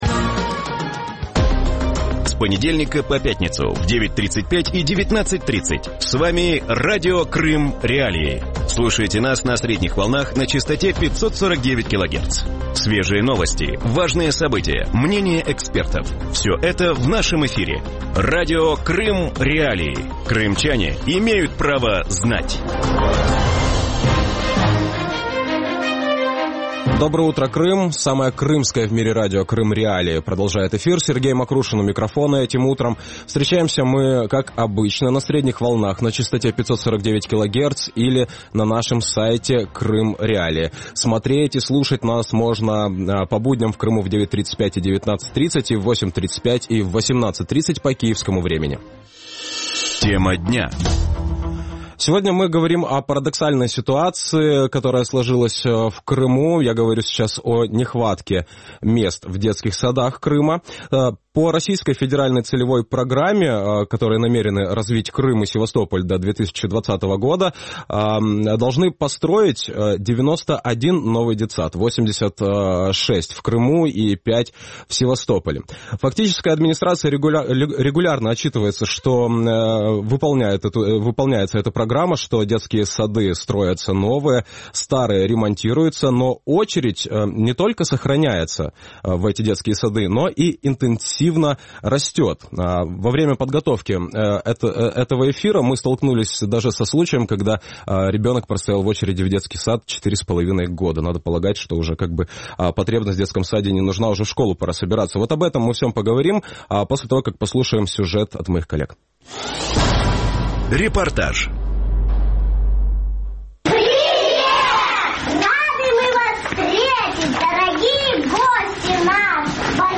У ранковому ефірі Радіо Крим.Реалії говорять про проблему нестачі місць в дитячих садках Криму.